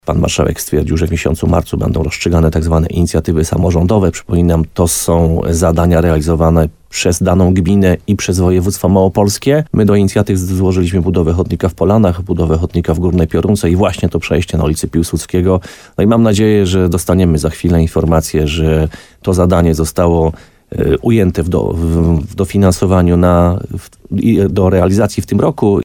– W ostatnim czasie odbyło się spotkanie w tej sprawie – mówi burmistrz Piotr Ryba.